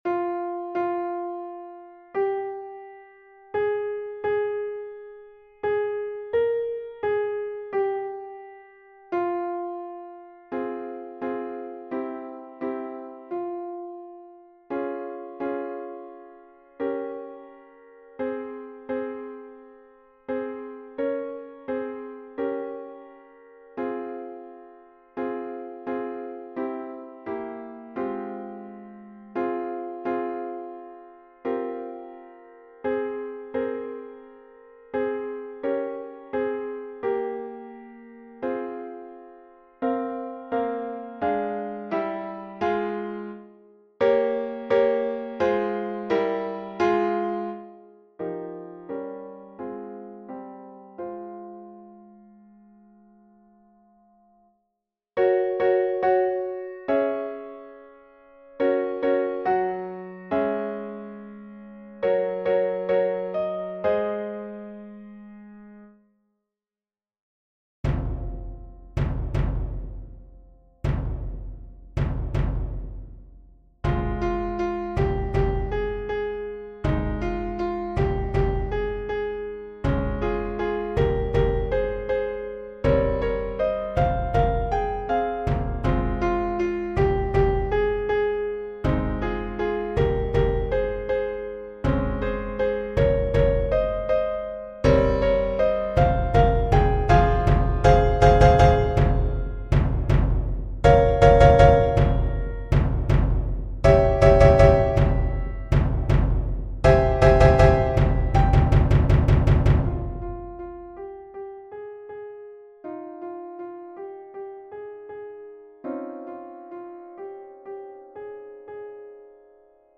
For SSAA female choir and frame drum
宗教音樂